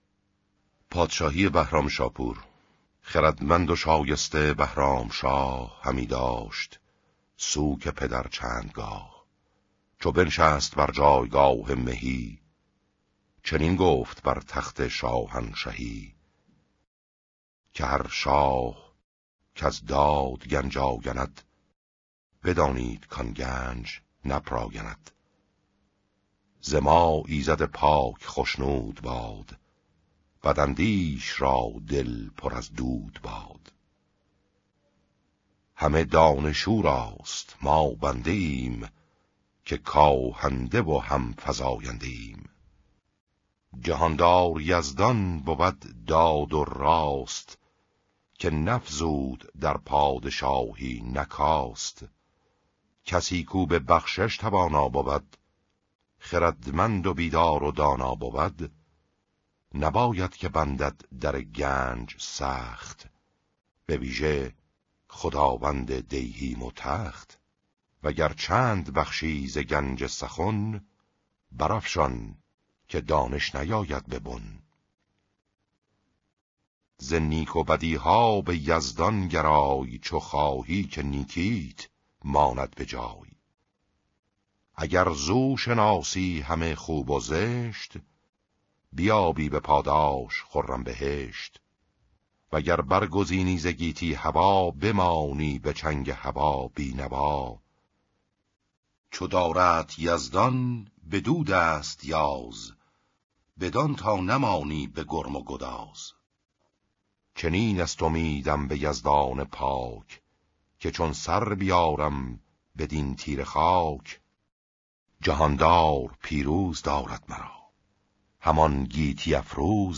بهرام شاپور :: شاهنامه صوتی